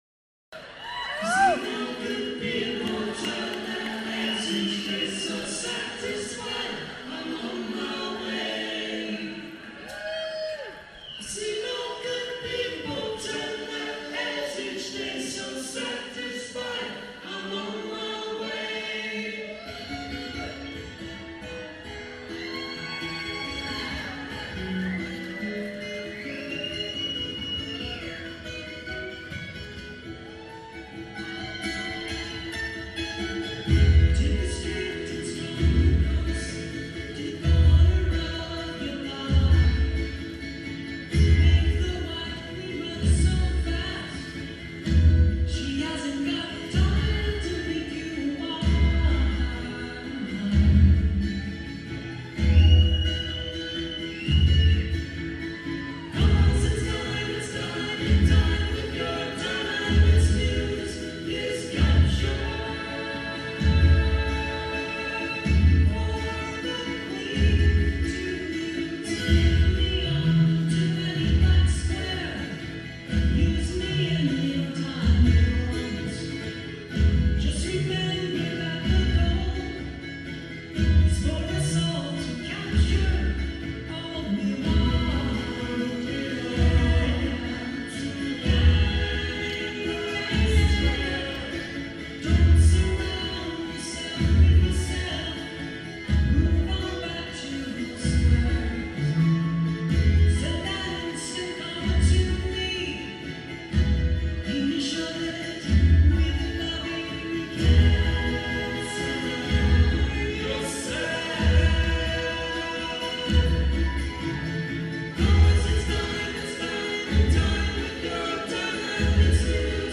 progressive rock
" recorded live on March 7.